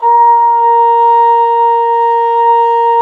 Index of /90_sSampleCDs/Roland LCDP12 Solo Brass/BRS_Cup Mute Tpt/BRS_Cup Ambient